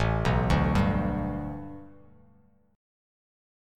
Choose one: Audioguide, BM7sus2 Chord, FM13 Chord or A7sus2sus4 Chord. A7sus2sus4 Chord